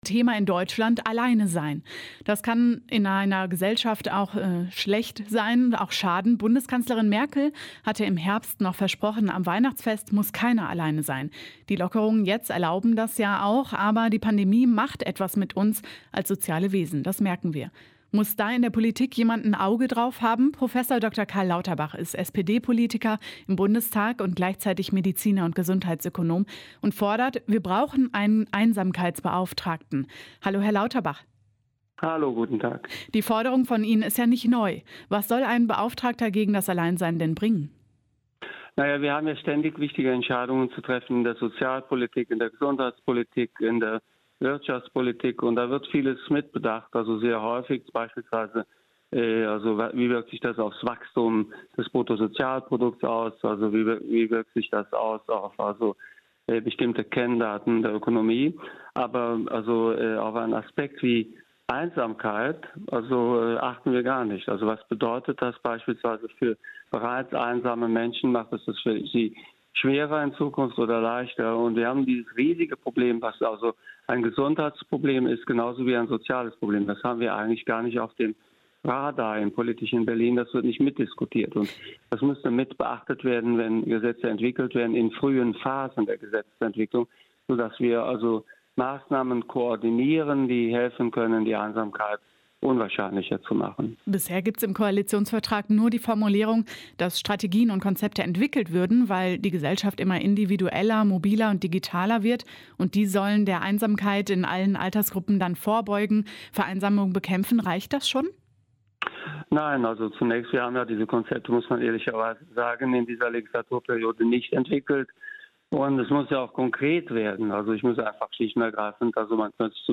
Ein Interview mit Professor Dr. Karl Lauterbach (SPD-Politiker, Mediziner und Gesundheitsökonom)